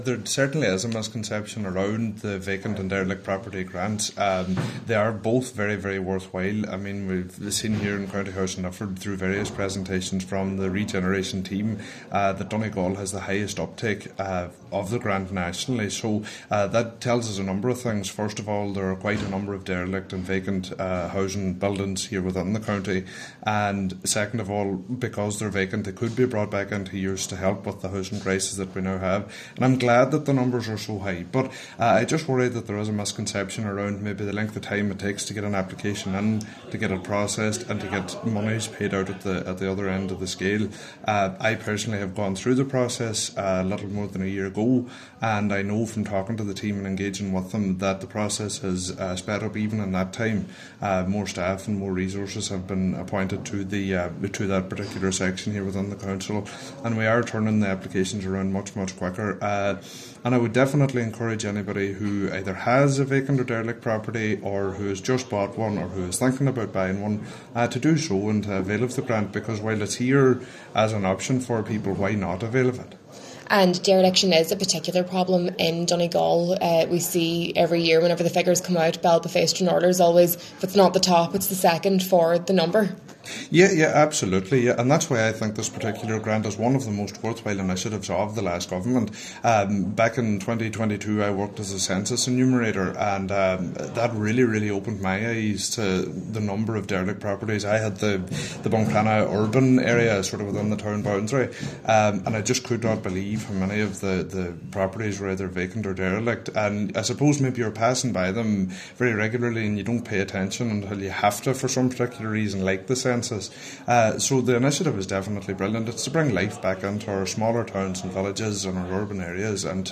Despite ongoing campaigns to highlight the different funding streams for vacant property owners, Councillor Bradley says there are still a lot of misconceptions: